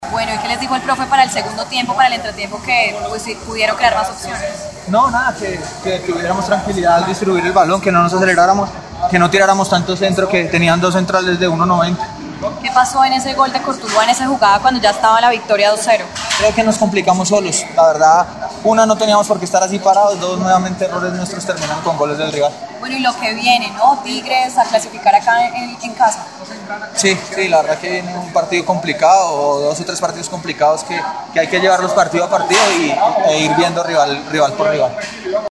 Todavía agitado por el despliegue físico del partido, David Mackalister Silva habló con los medios de comunicación y contó sus impresiones de lo que fue el partido, especialmente en el segundo tiempo cuando ingresó al campo de juego en lugar de Kouffaty.